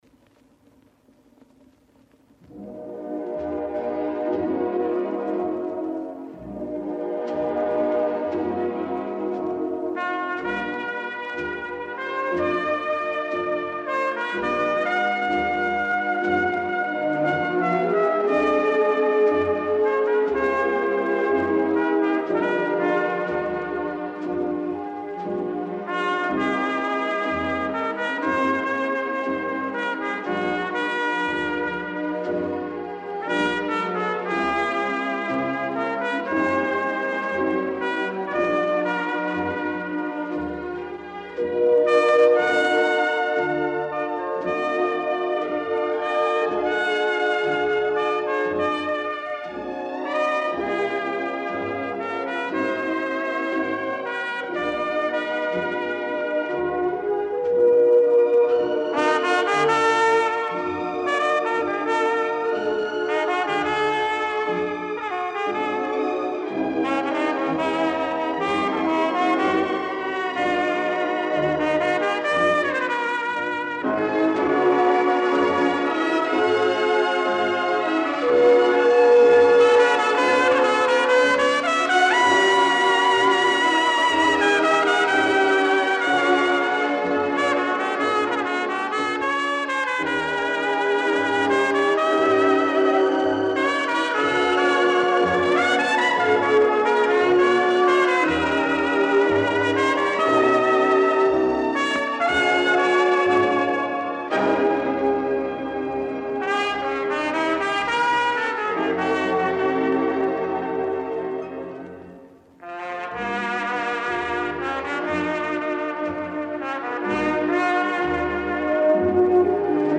эстрадный орк.